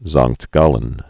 (zängkt gälən) also Saint Gall (sānt gôl, gäl, säɴ gäl)